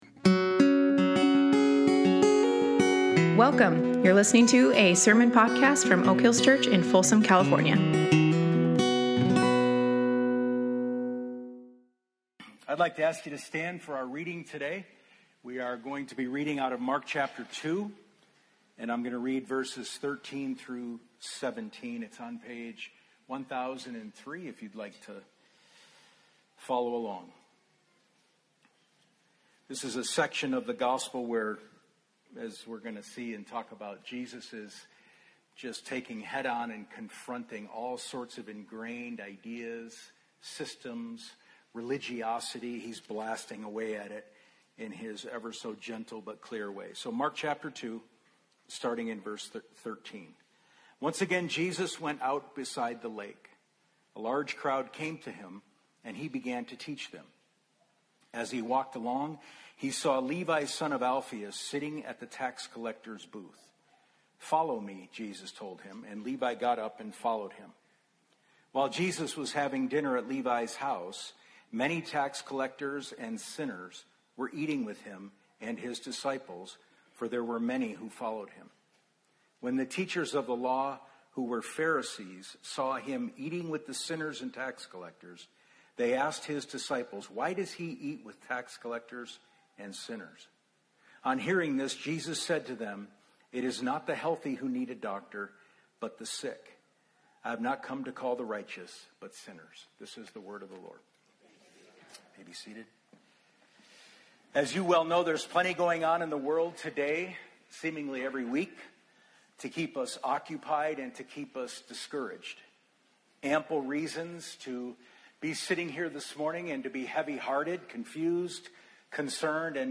Mark 2:13-17 Service Type: Sunday Morning This is how othering happens.